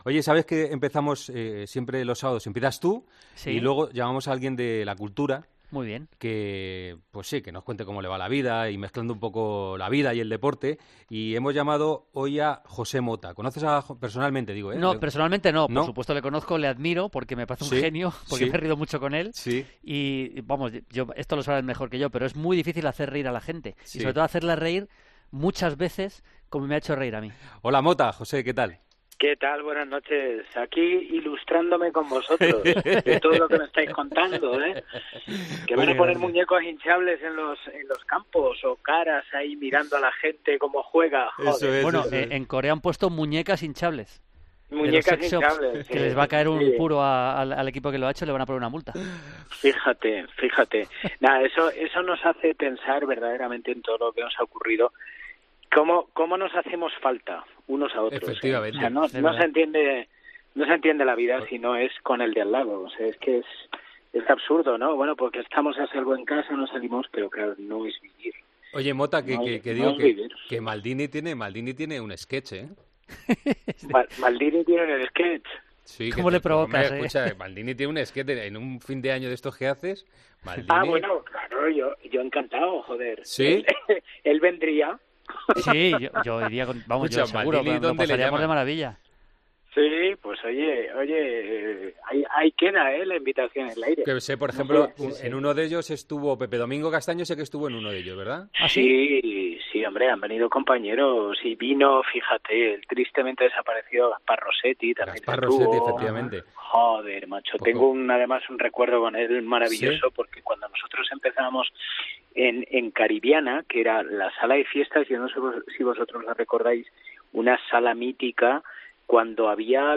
AUDIO: El humorista ha estado en el tramo final de Tiempo de Juego para analizar cómo el humor puede ser una vía de escape en momentos tan complicados.